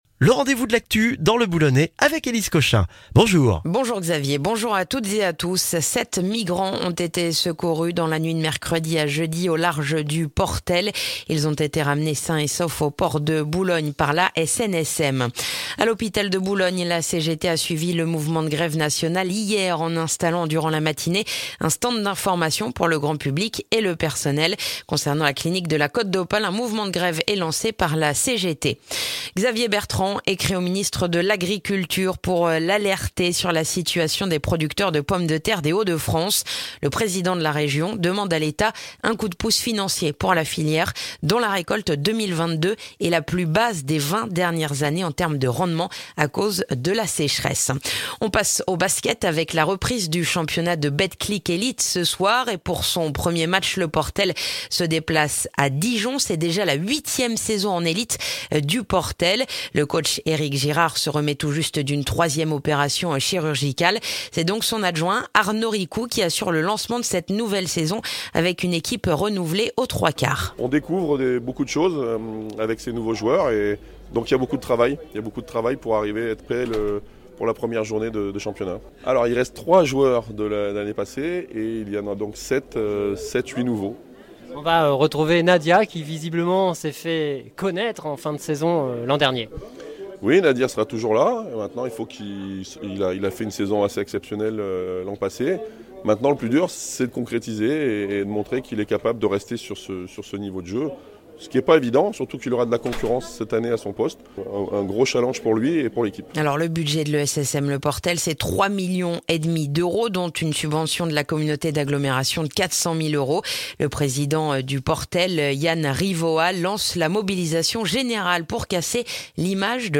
Le journal du vendredi 23 septembre dans le boulonnais